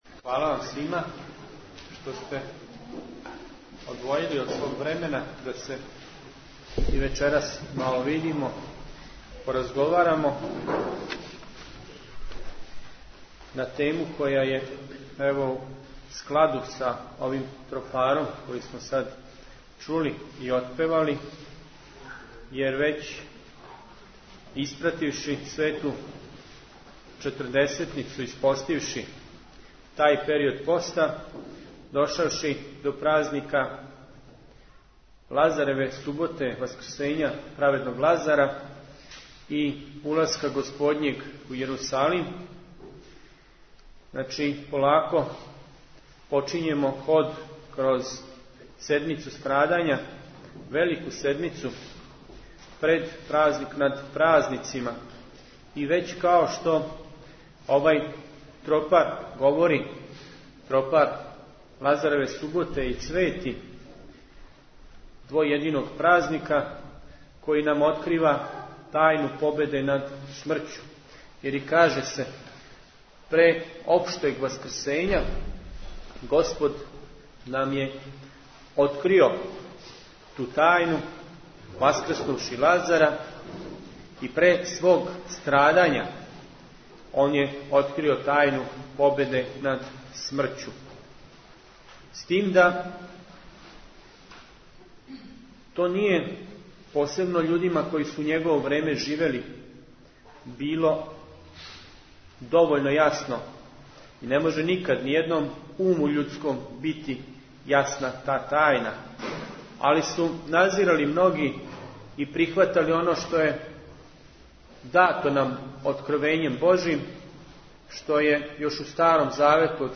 Звучни запис предавања
Футог